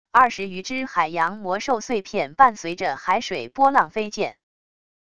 二十余只海洋魔兽碎片伴随着海水波浪飞溅wav音频